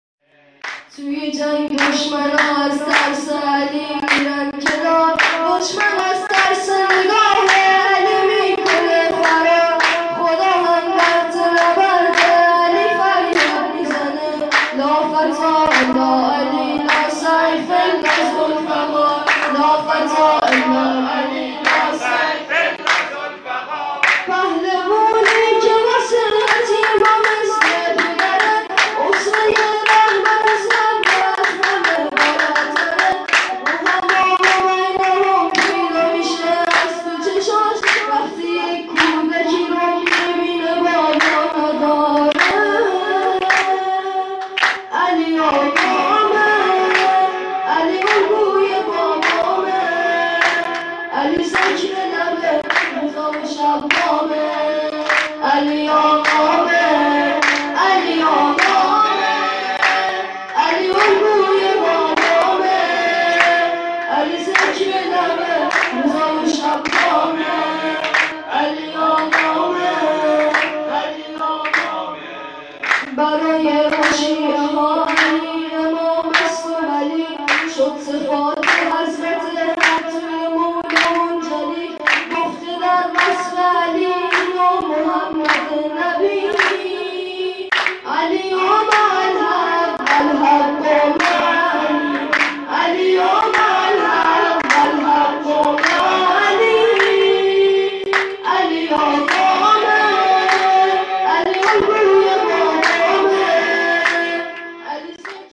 برگزاری جشن عید بزرگ شیعیان عید غدیر با نوای مداح اهل بیت
جشن ها
مداحان نوجوان